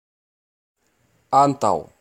Ääntäminen
IPA : /ɒv/ IPA : /ʌv/ IPA : /əv/